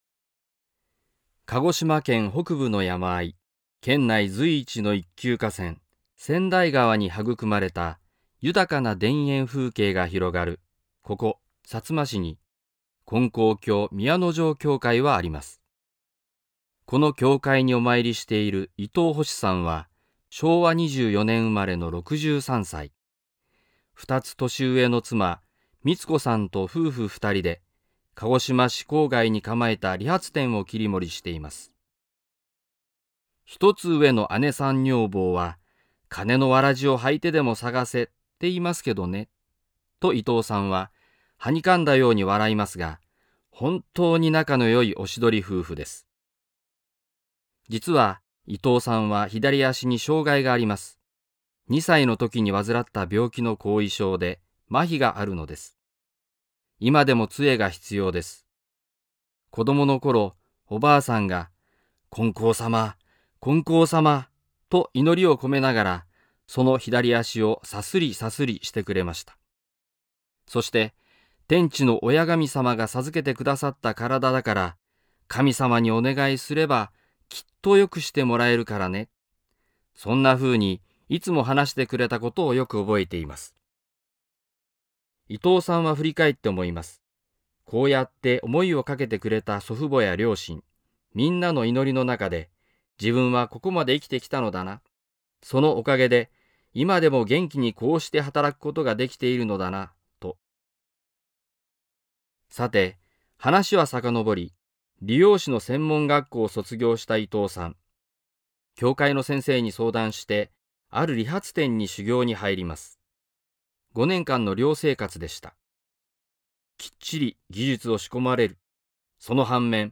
信者さんのおはなし